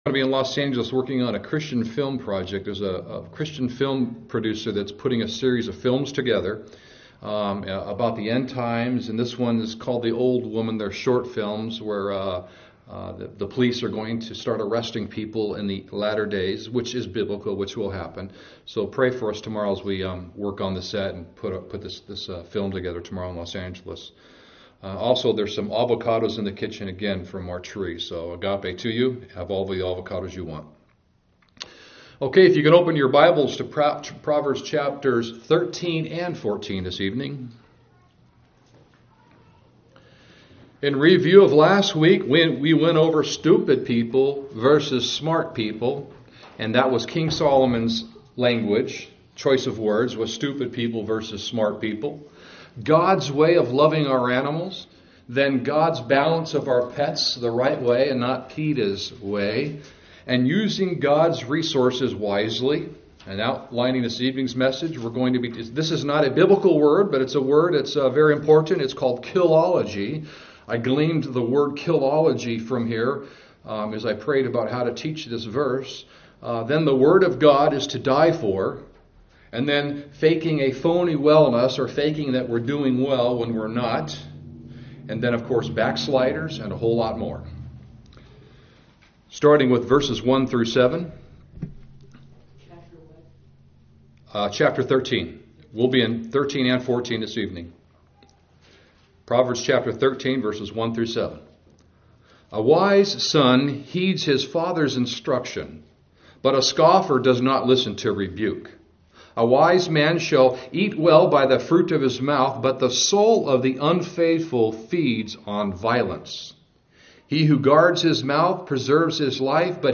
Sermon audio of Proverbs Chapters 13 & 14 (Killology, the Word of God is to die for, I’d rather be a Puritan than emergent and/or contemporized for & more)